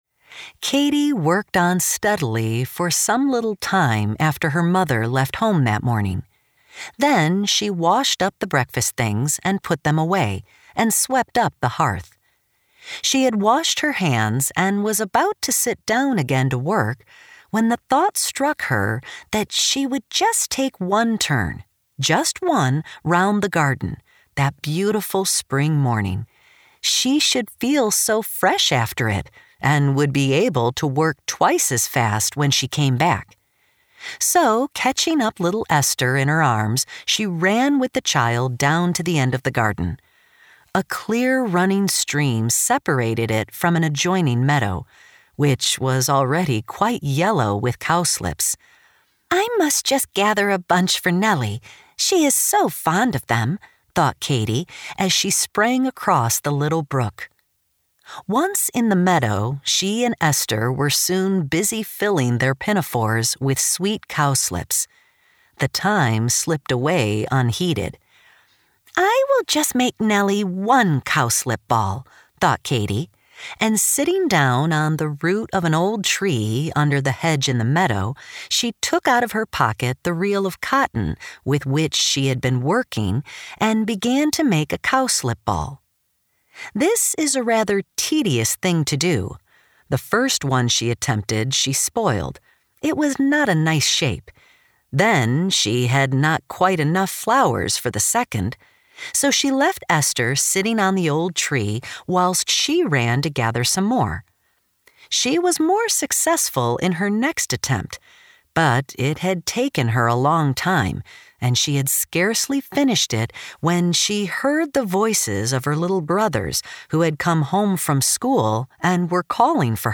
Struck-by-Lightning-Audiobook-Sample.mp3